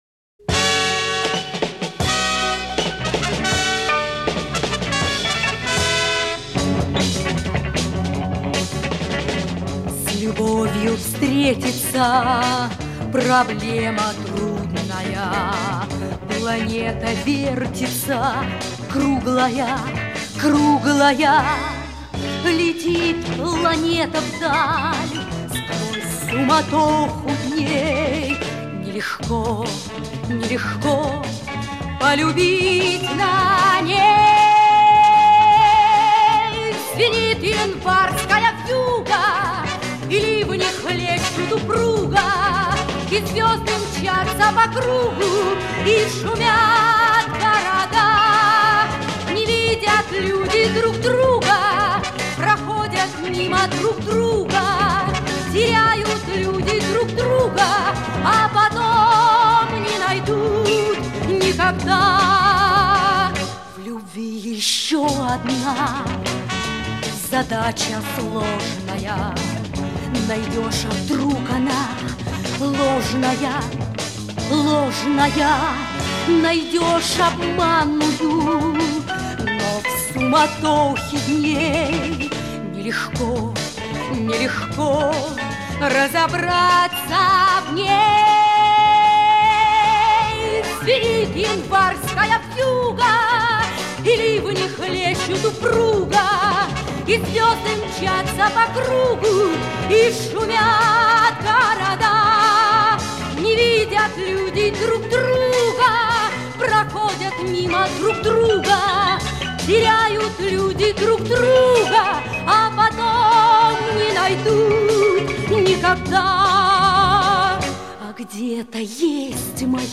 песня из к/ф